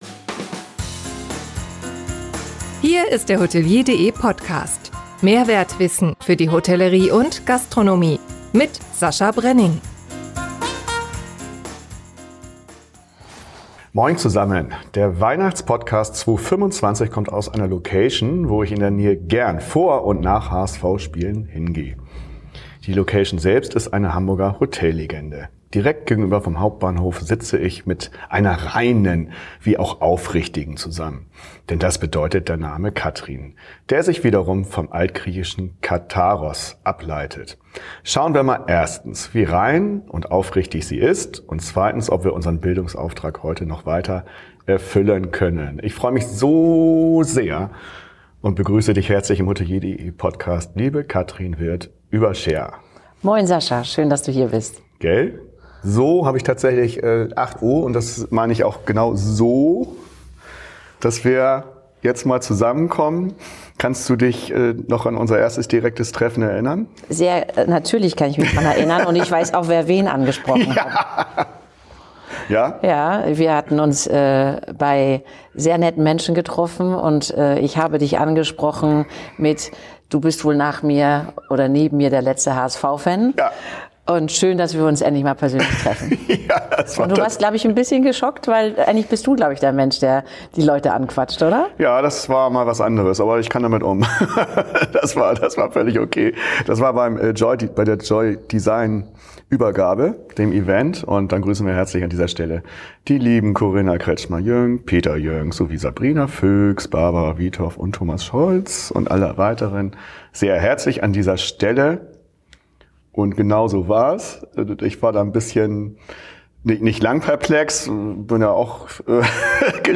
Dies wird garniert mit persönlichen Einblicken, viel Lachen und einer hohen, gegenseitigen Sympathie.